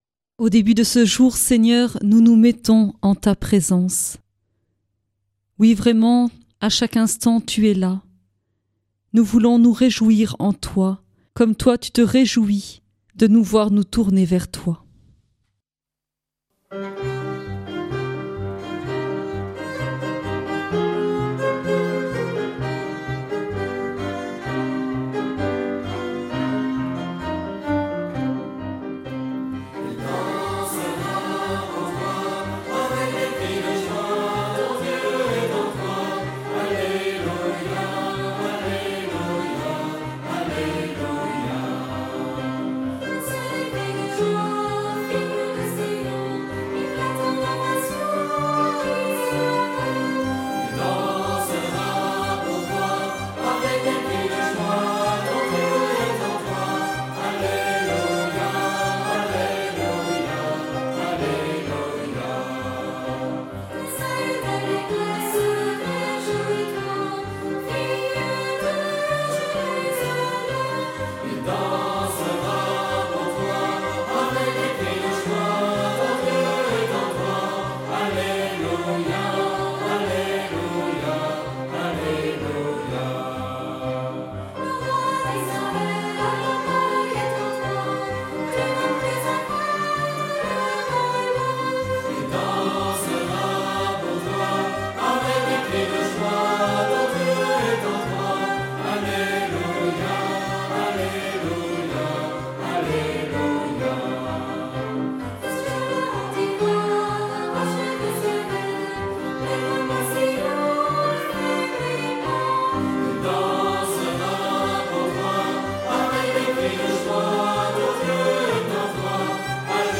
Une émission présentée par Groupes de prière